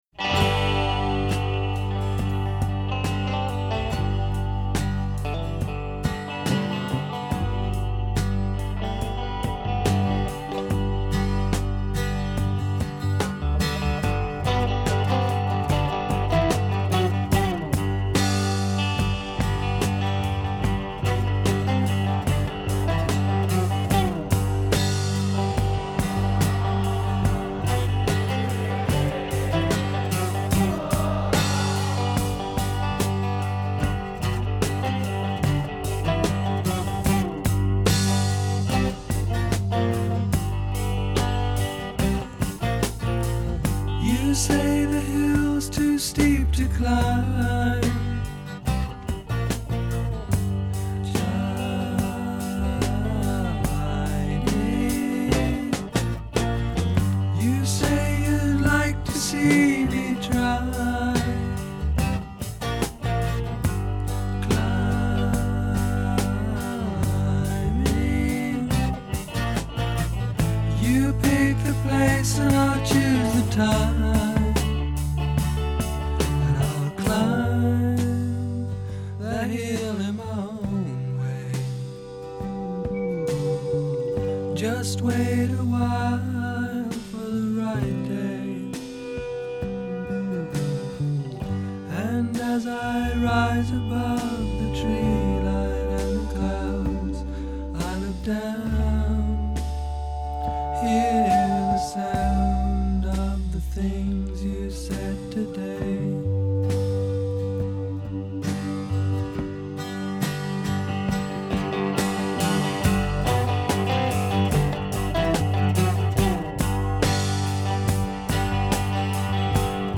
Genre: rock,alternative rock
progressive rock